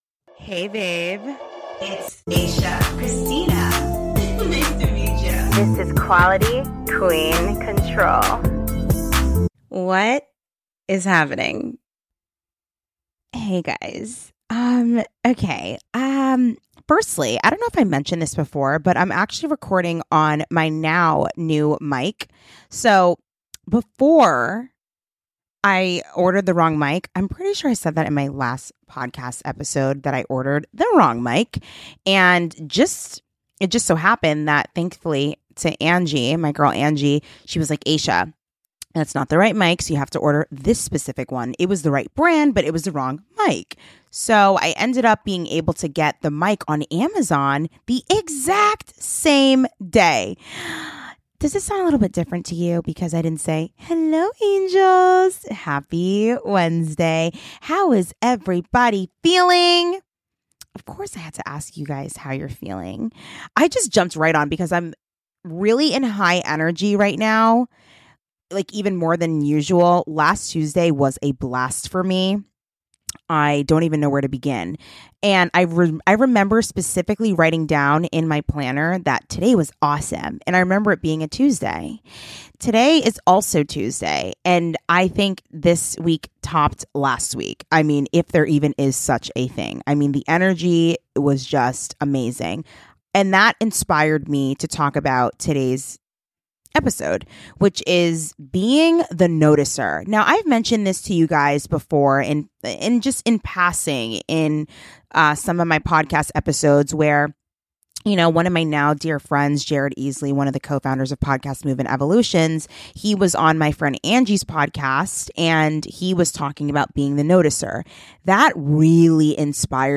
Dress For Success & Being the Noticer! (Audio Vlog)